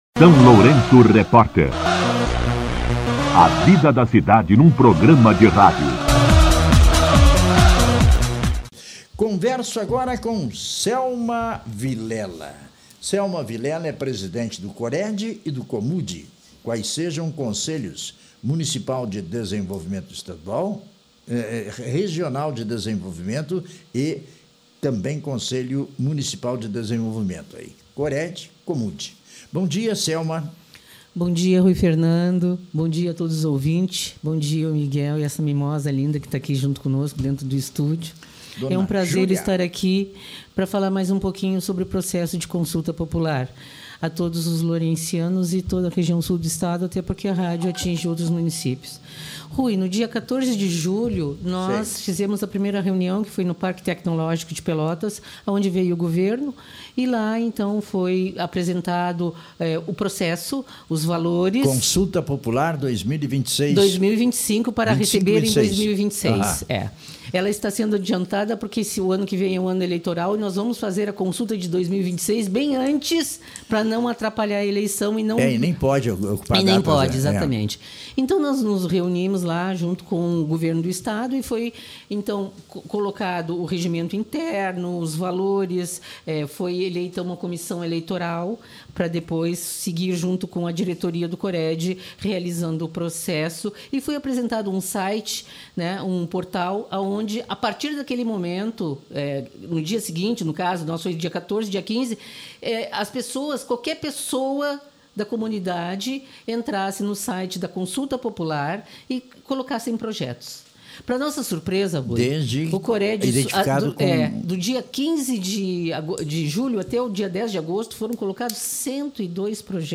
CONSULTA POPULAR: DATAS E PROCESSOS EM ENTREVISTA